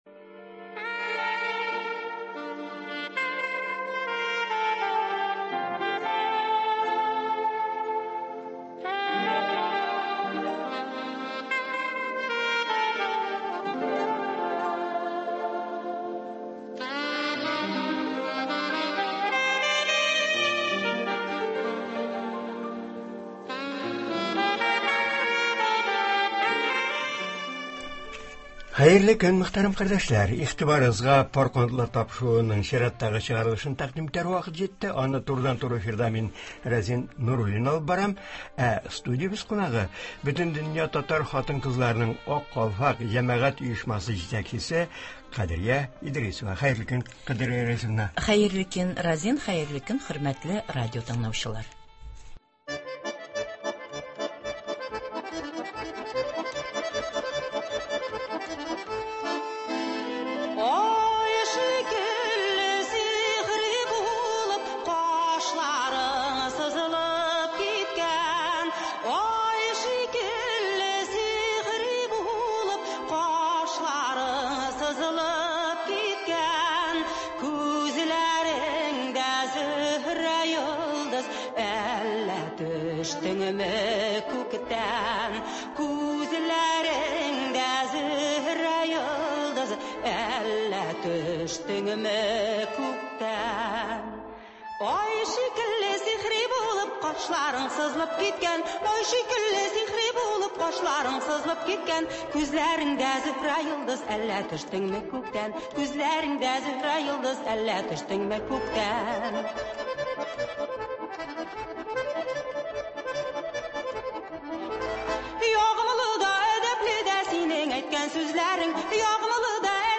турыдан-туры эфирда
сөйли һәм тыңлаучылар сорауларына җавап бирә